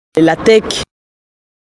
prononciation La tek.
la tek_prononciation.mp3